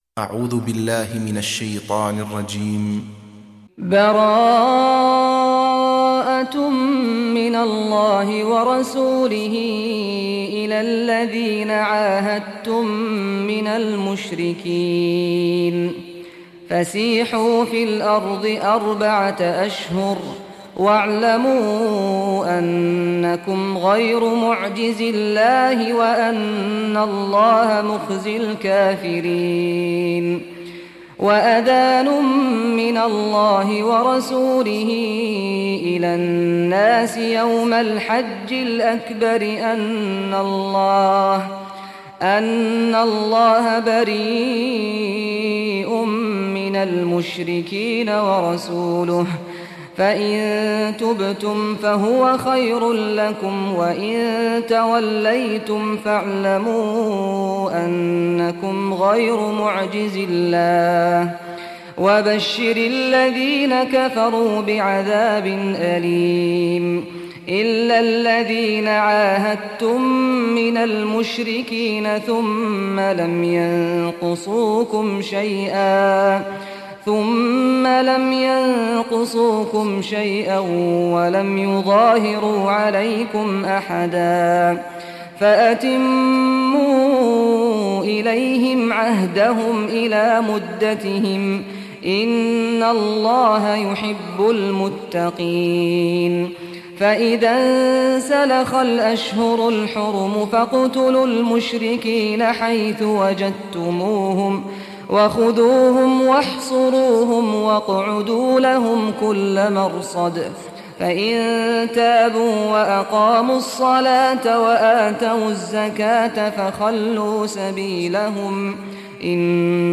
Surah At-Taubah Recitation by Sheikh Saad Ghamdi
Surah At-Taubah, listen or play online mp3 tilawat / recitation in Arabic in the beautiful voice of Sheikh Saad Al Ghamdi.